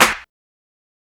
Claps
DrClap3.wav